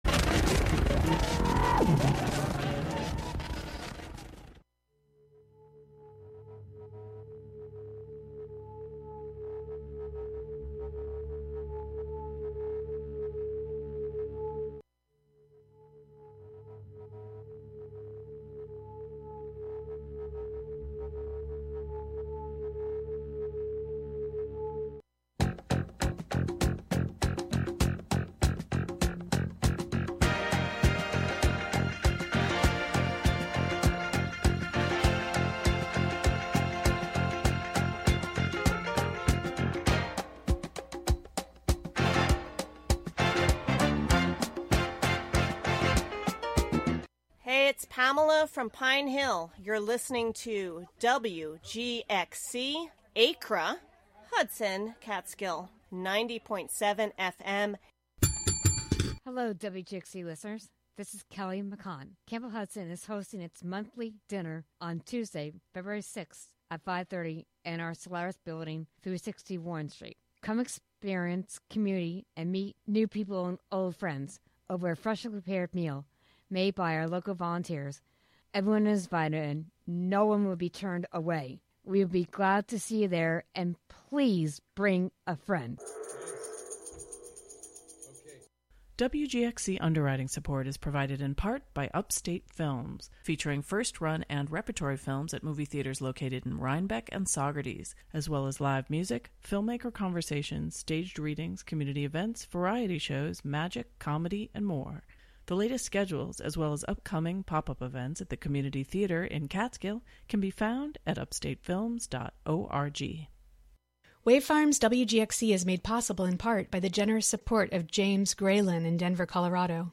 Contributions from many WGXC programmers.
The show is a place for a community conversation about issues, with music, and more.